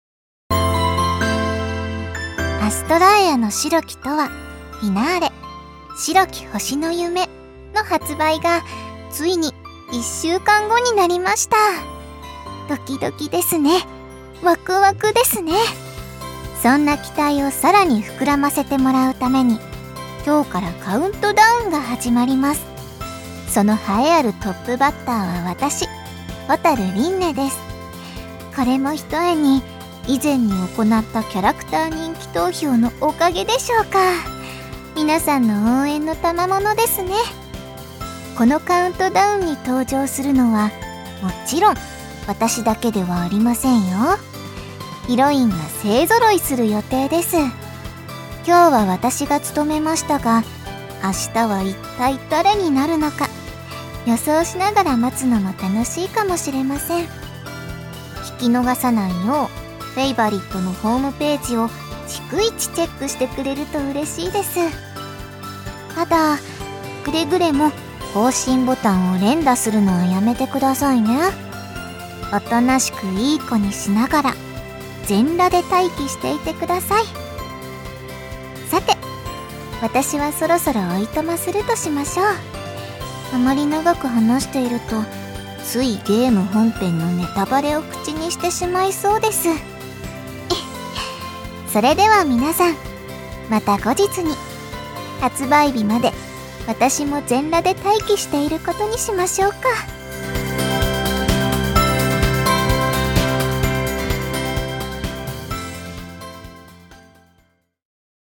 『アストラエアの白き永遠 Finale』 発売7日前カウントダウンボイス（りんね）を公開